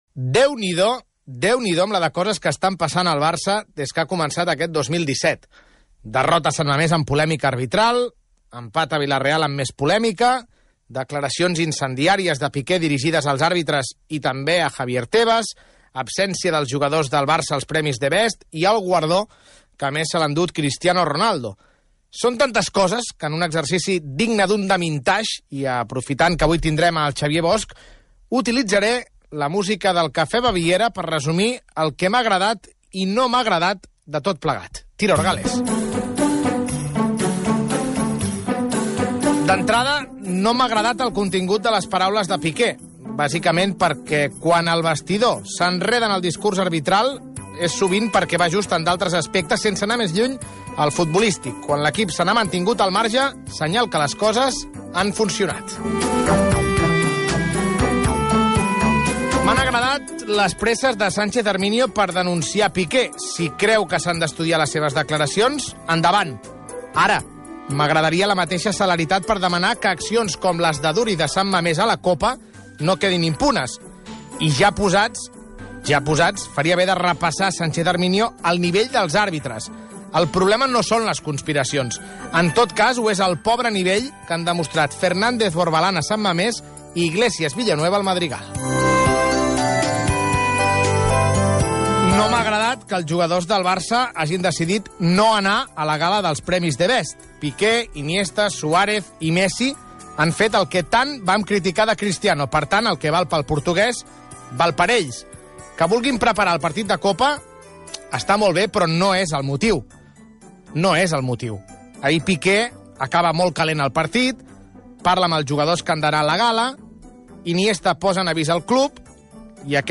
Esportiu
FM